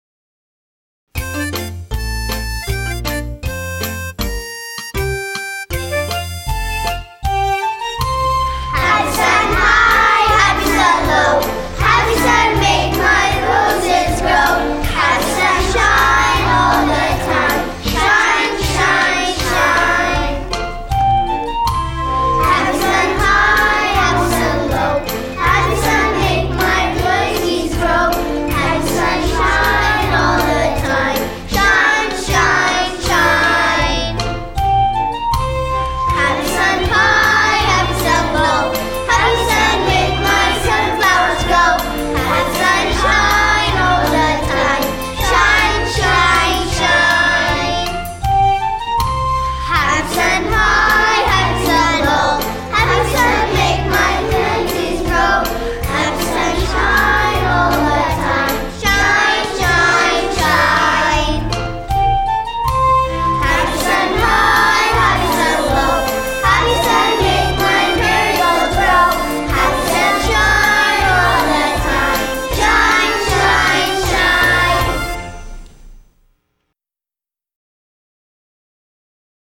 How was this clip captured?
The worst recordings I have ever heard have come from elementary schools.